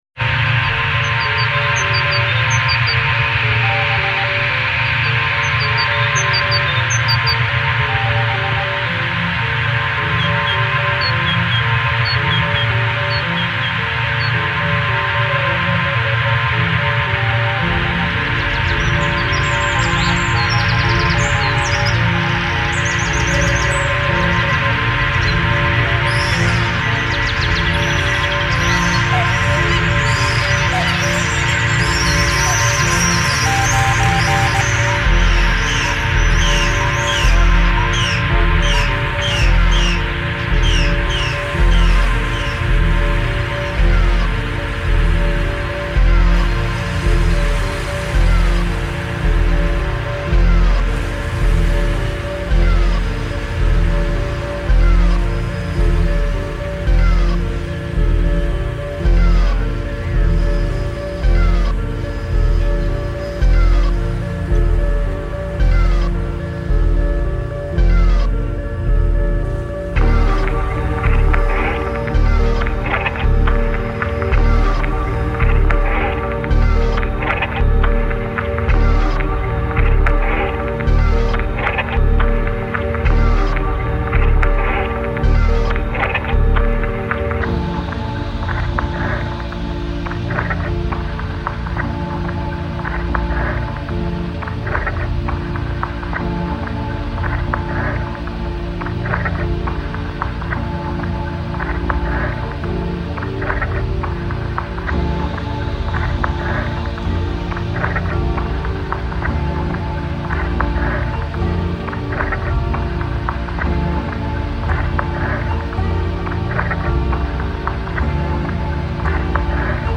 [ TECHNO | AMBIENT ]